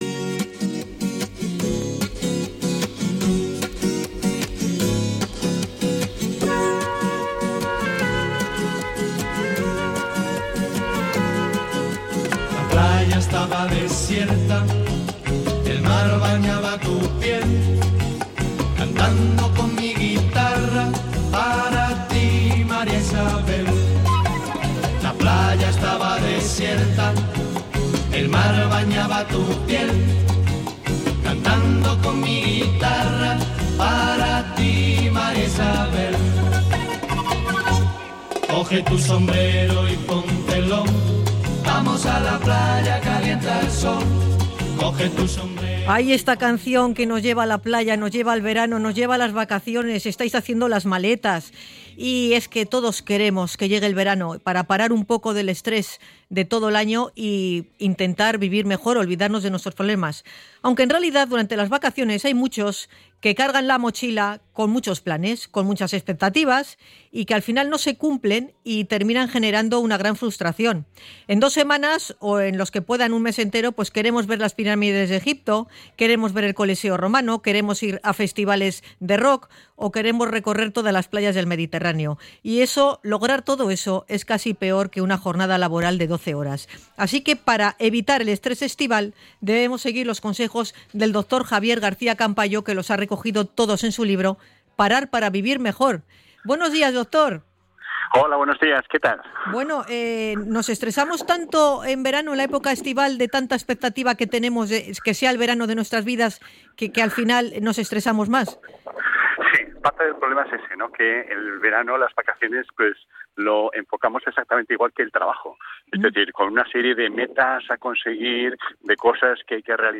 Podcast Ciencia y salud